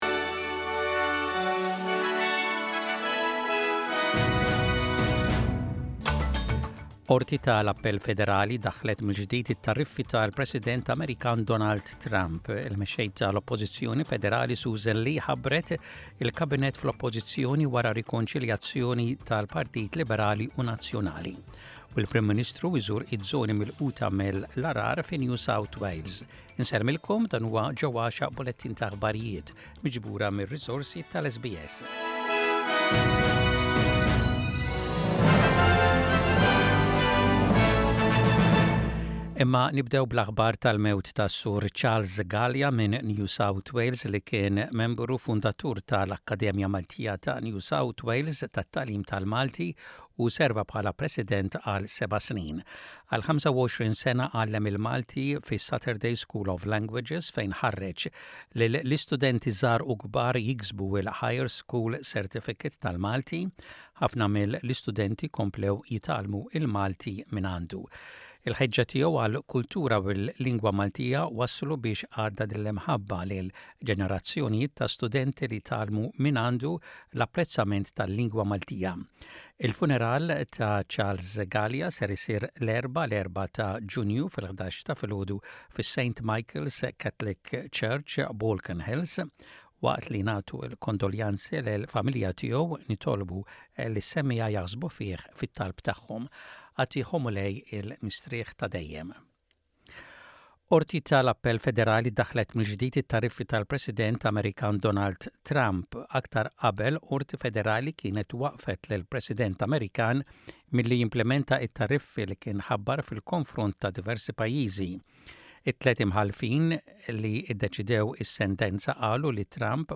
Aħbarijiet bil-Malti: 30.05.25